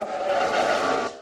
Sound / Minecraft / mob / horse / skeleton / idle1.ogg
should be correct audio levels.